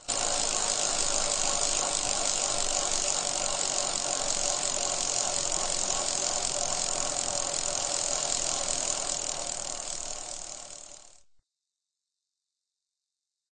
bike_wheel_spin.ogg